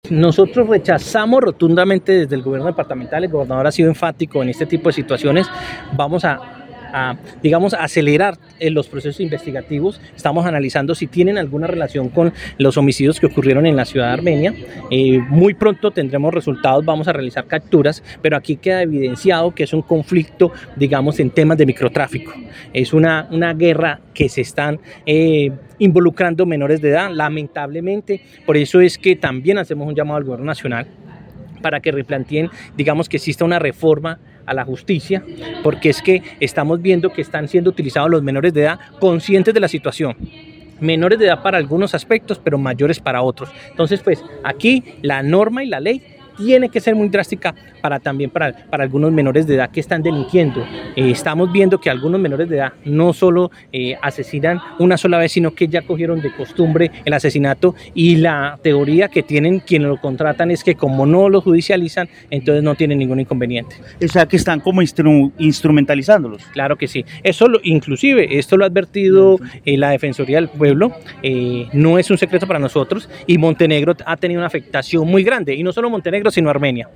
Jaime Andrés Pérez, secretario del interior